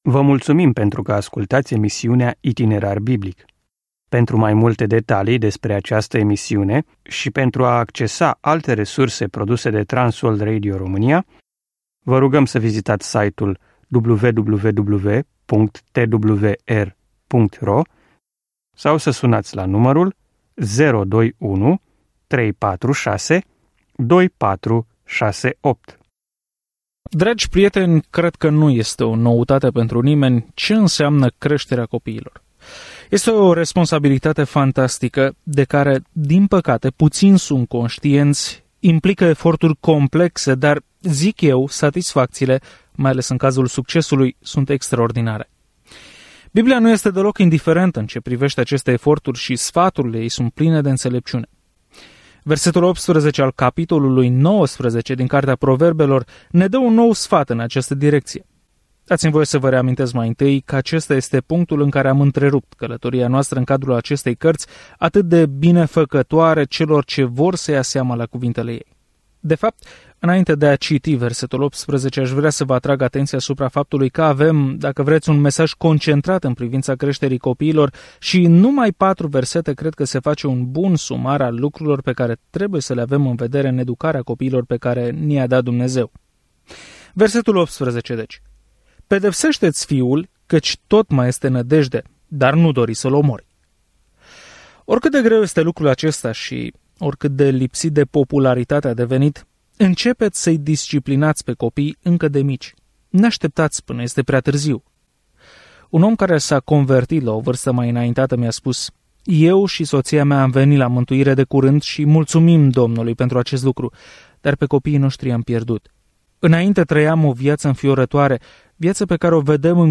Scriptura Proverbele 19:18-29 Proverbele 20 Ziua 15 Începe acest plan Ziua 17 Despre acest plan Proverbele sunt propoziții scurte extrase din experiențe lungi care învață adevărul într-un mod ușor de reținut - adevăruri care ne ajută să luăm decizii înțelepte. Călătoriți zilnic prin Proverbe în timp ce ascultați studiul audio și citiți versete selectate din Cuvântul lui Dumnezeu.